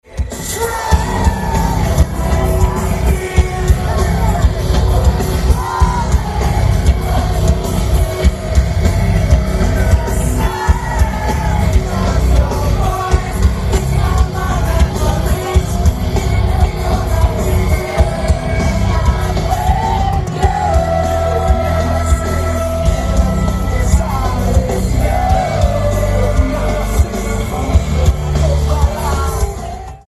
Venue Type:Outdoor
• Comments: Very good sounding recording.